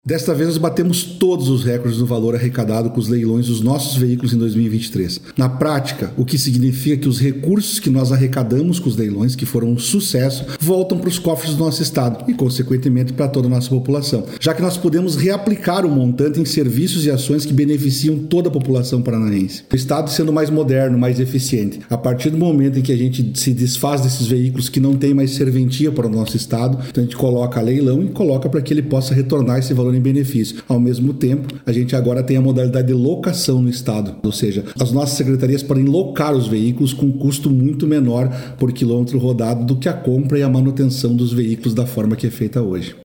Sonora do secretário da Administração e da Previdência, Elisandro Frigo, sobre a arrecadação de R$ 7 milhões com leilões de veículos em 2023